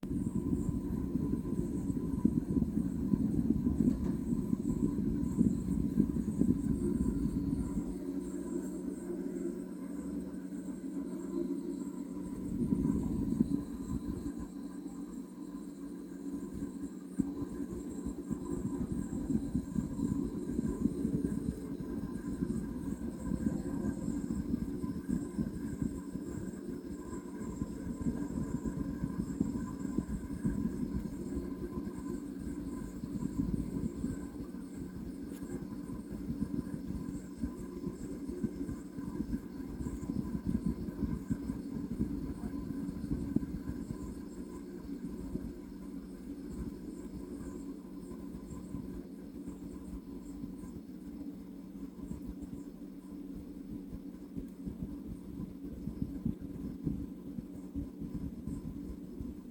Bruit de l'unité intérieure (enregistrement joint ventilation arrêtée)
Pour le 1er mp3, non l'autre UI ne tournait pas, c'est cet UI qui venait d'arrêter de souffler.